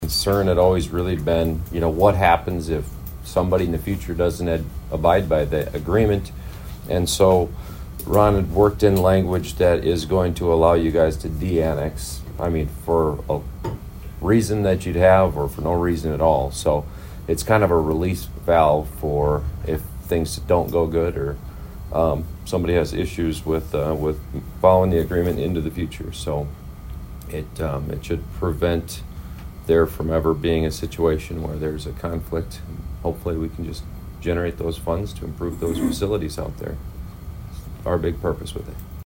Aberdeen Mayor Travis Schaunaman talks about the agreement reached by City Attorney Ron Wager in case the county wants to end the annexation.